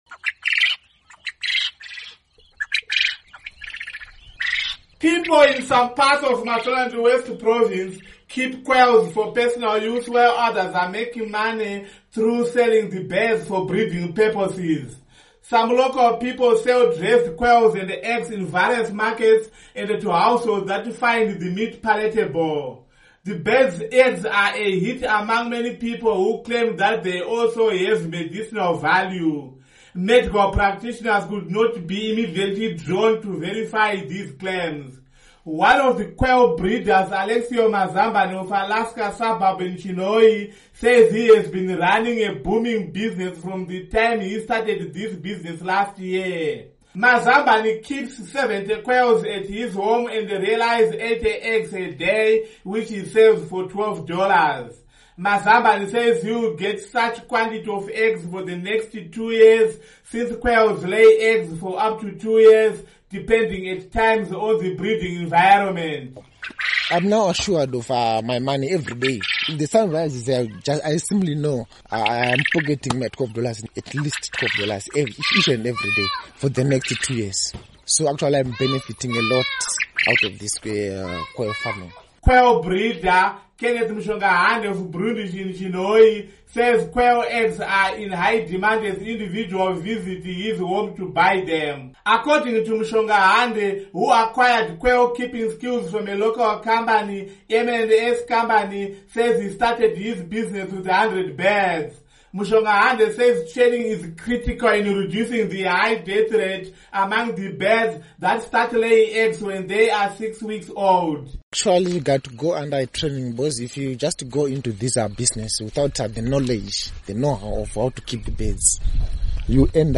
Report on Quail Birds